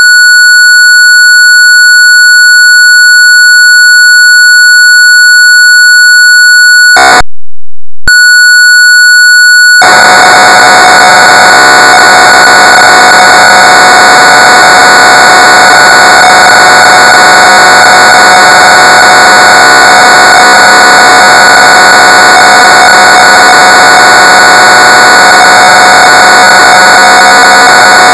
ПК-01 Львов WEB Tape Loader